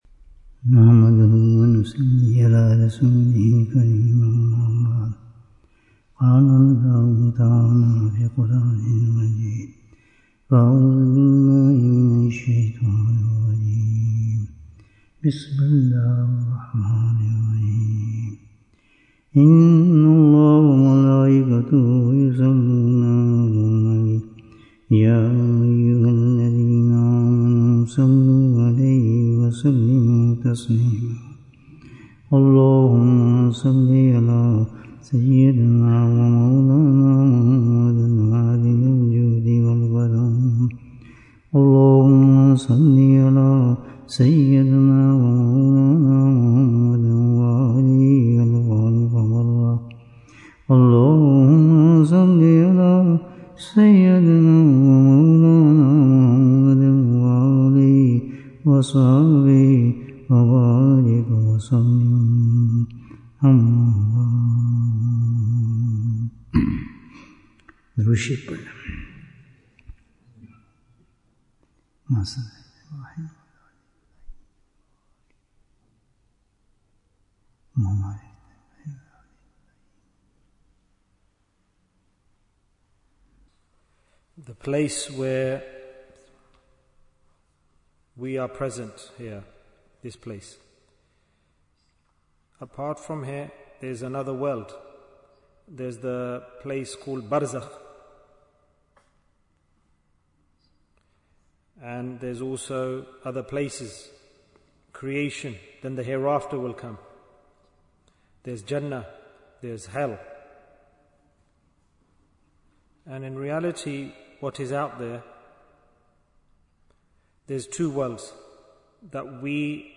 Wazifa for Every Calamity Bayan, 70 minutes18th September, 2025